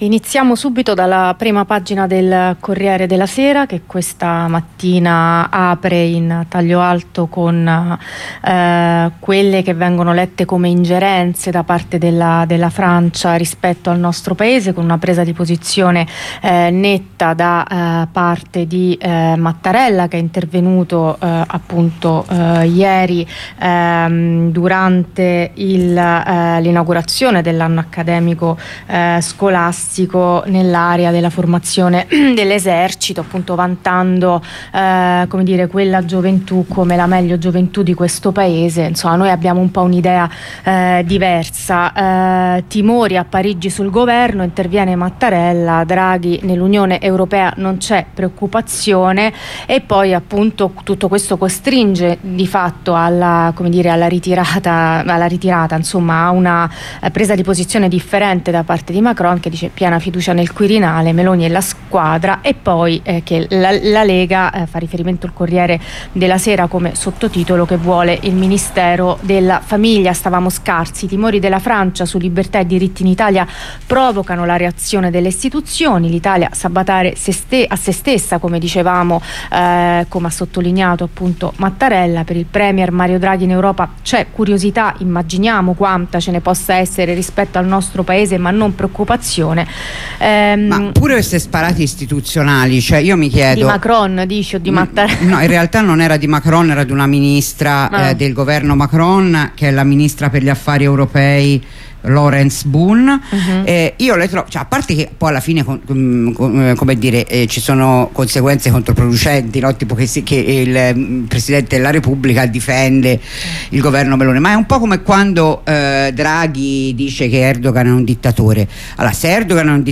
Rassegna stampa di sabato 8 ottobre 2022
con una compagna che presenta la passeggiata di domani 9 ottobre al Pigneto: Take back the streets